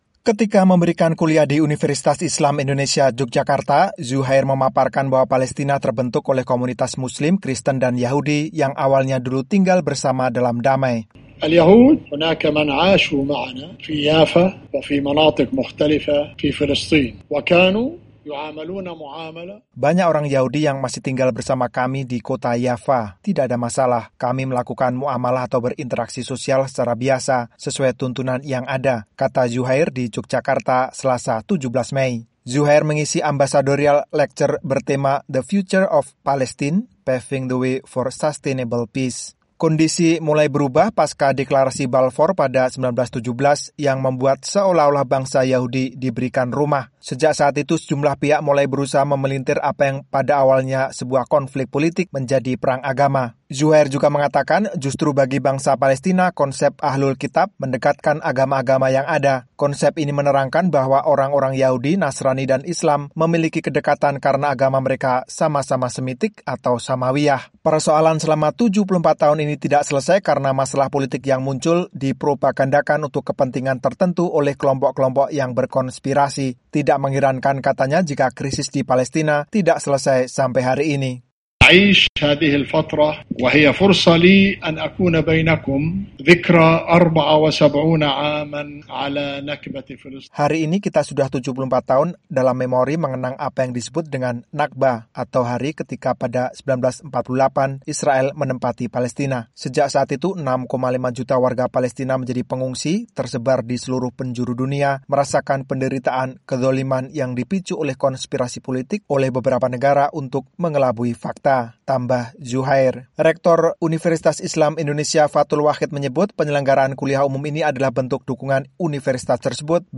Duta Besar Palestina untuk RI, Dr. Zuhair Al Shun (berdiri) ketika menyampaikan Ambassadorial Lecture di kampus UII, Yogyakarta, Selasa (17/5).